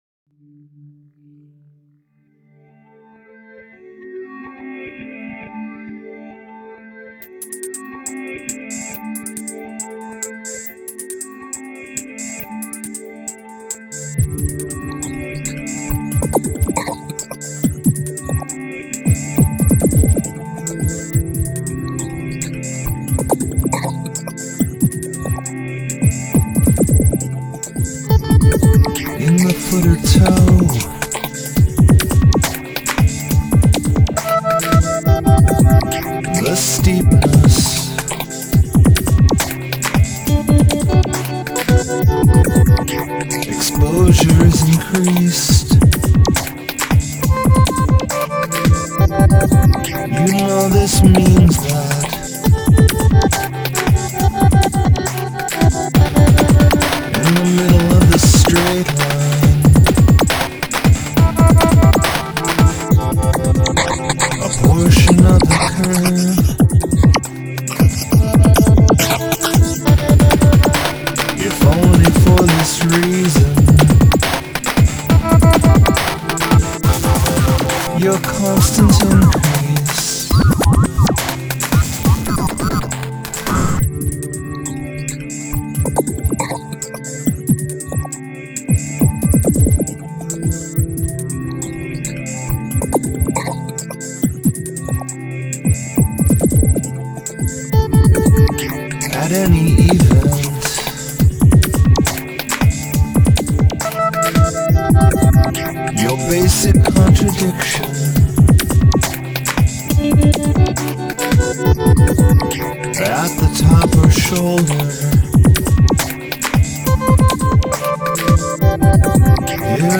t hey were recorded using the four track first version of v ision audio and then later imported into p ro t ools free and reedited. t he dat master had some corruption but the sound of it fits the material in a weird way. the steepness